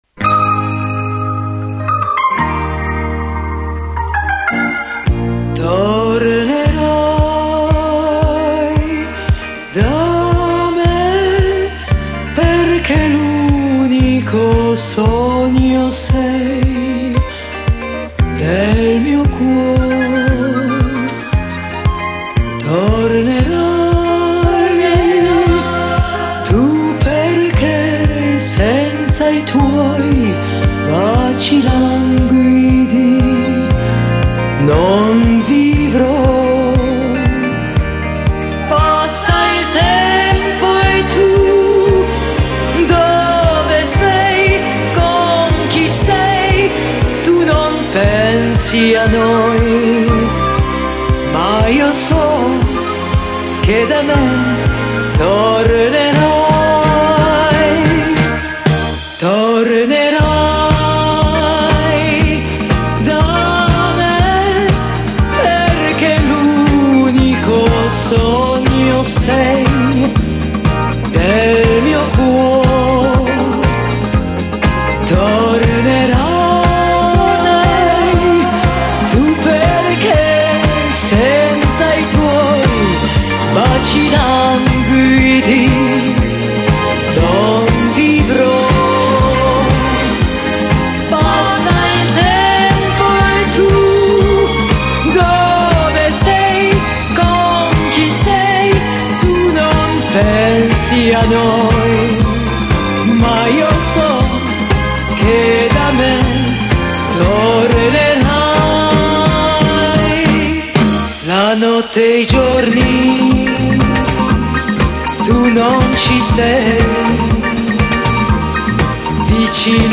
La versione disco degli anni '70